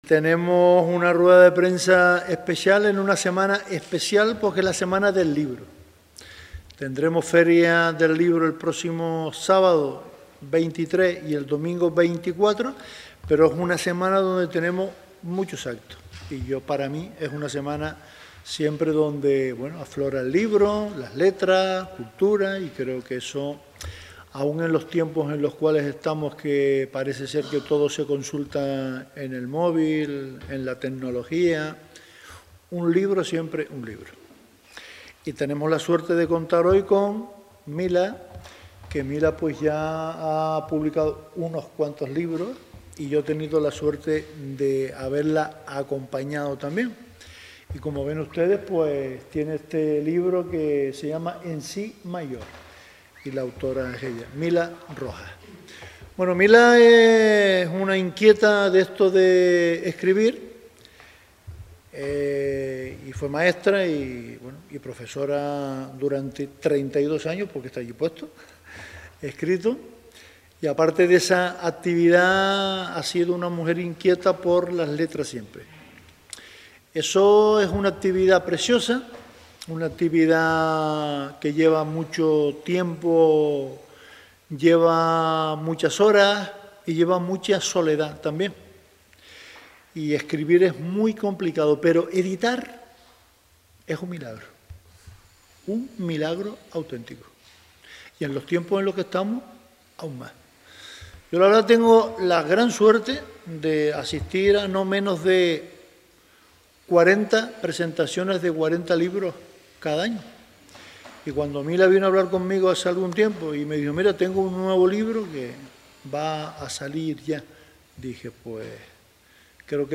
La Orotava. Presentación del libro ‘En sí mayor’